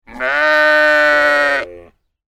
دانلود آهنگ گوسفند 2 از افکت صوتی انسان و موجودات زنده
دانلود صدای گوسفند 2 از ساعد نیوز با لینک مستقیم و کیفیت بالا
جلوه های صوتی